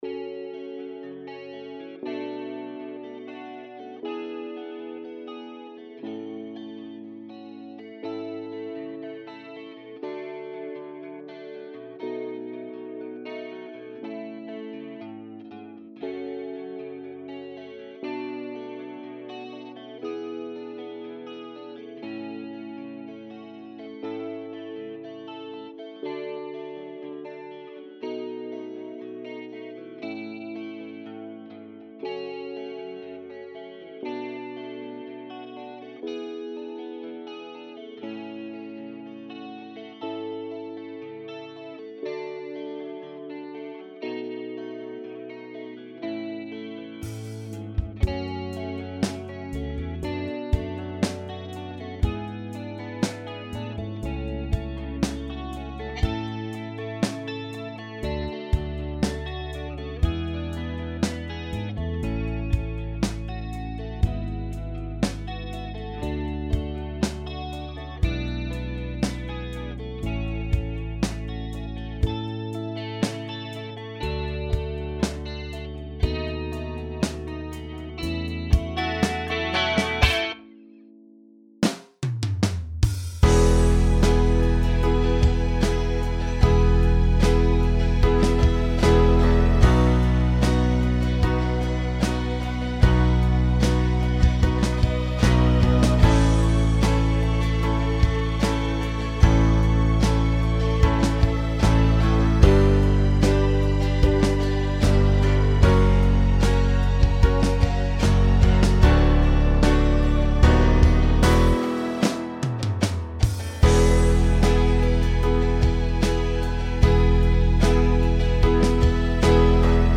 Atlanthropia” is a rock opera that tells the tragic story of fictional people who might well have existed.